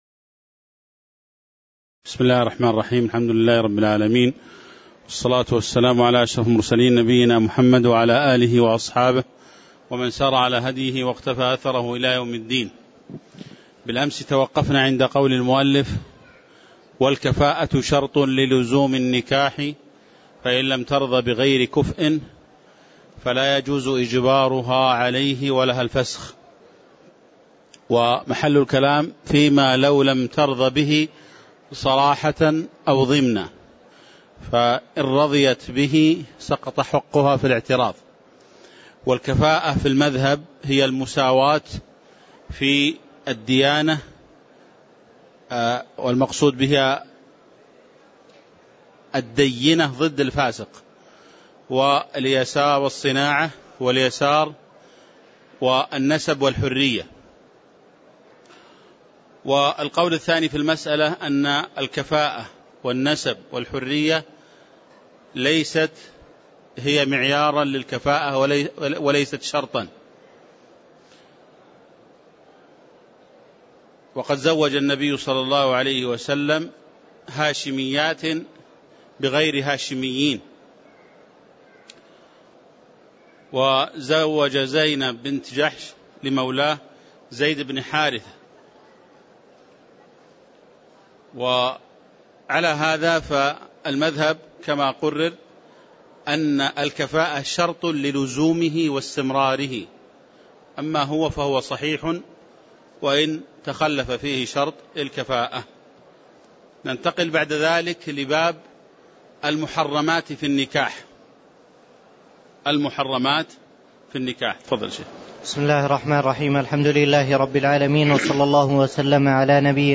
تاريخ النشر ١ جمادى الأولى ١٤٣٨ هـ المكان: المسجد النبوي الشيخ